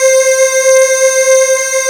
MELLOW C5.wav